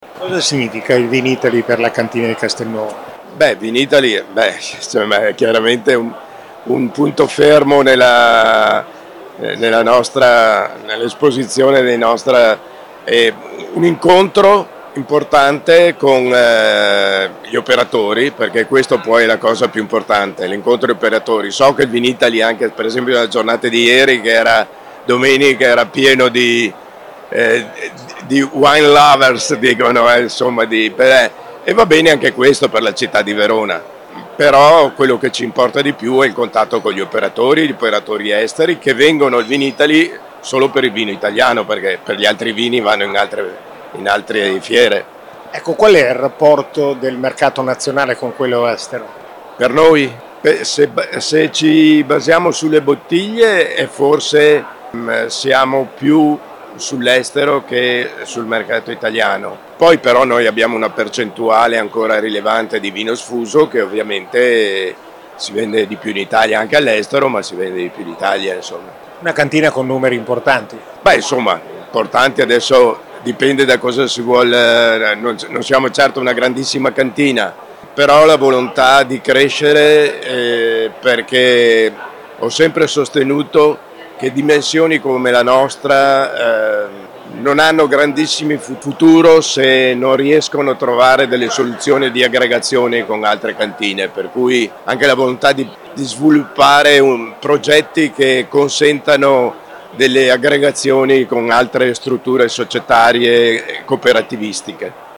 Al nostro microfono